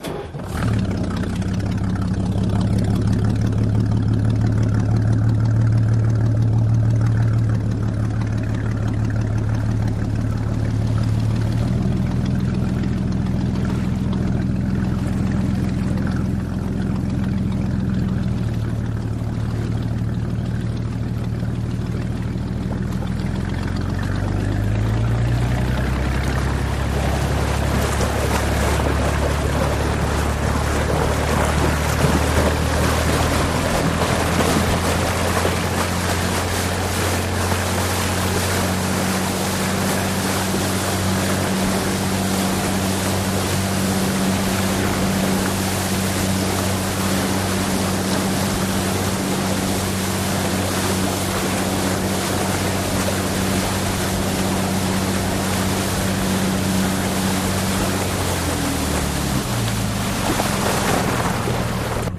Boat Engine
V6 Chris Craft Start, Accelerates Constant, Bow Wash